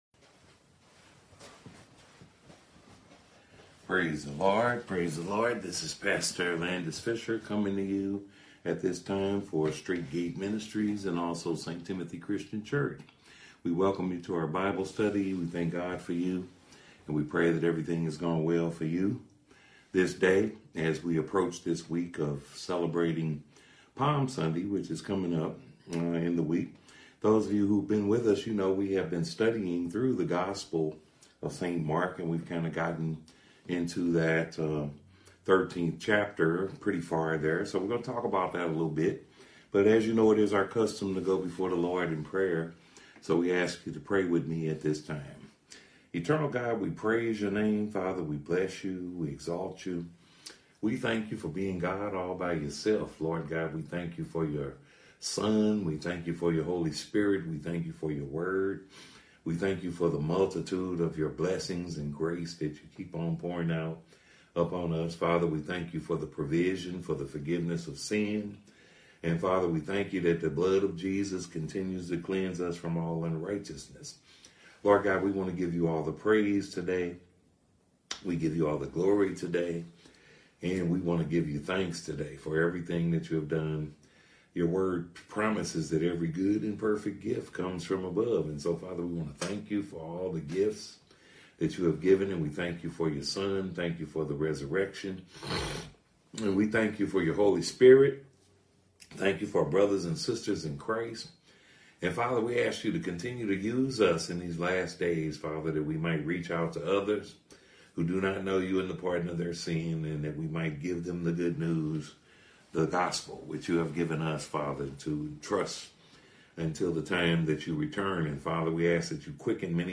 printEthnic Clashes During The First Advent Of Jesus The Messiah (Bible Study)